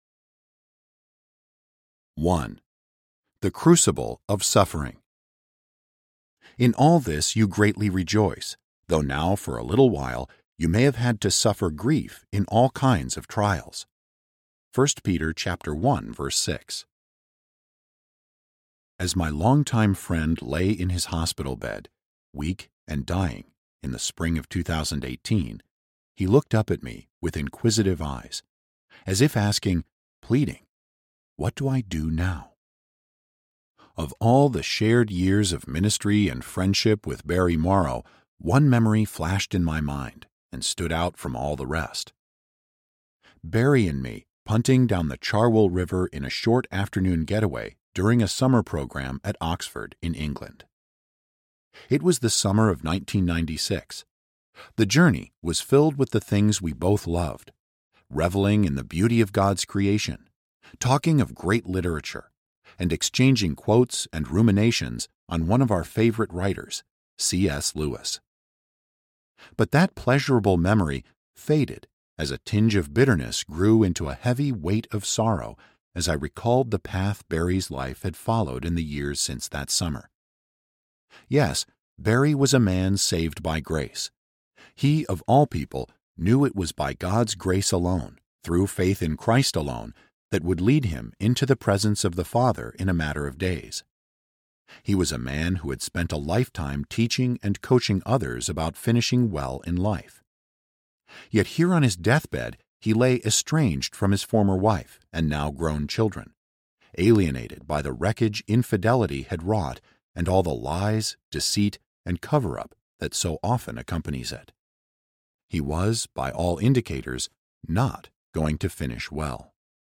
Shaped by Suffering Audiobook
Narrator